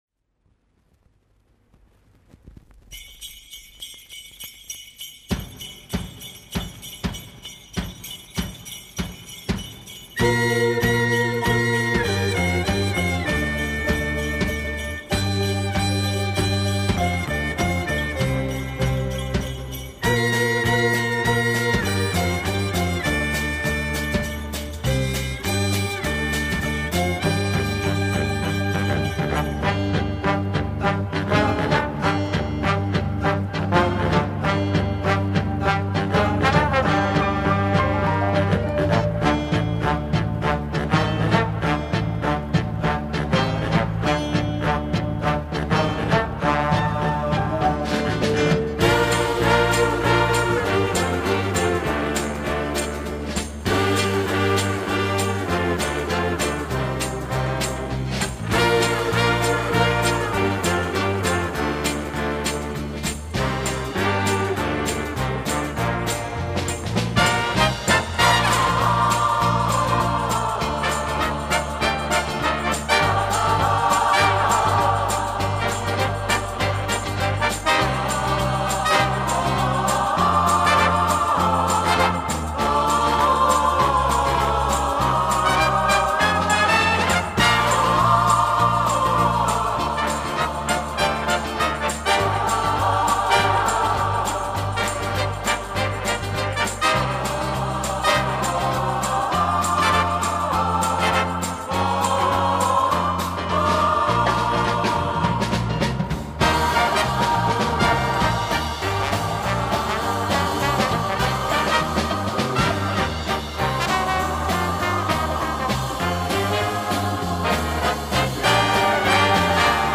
LP转码128Kbps。嗤嗤啦啦的音轨声音听得到吗？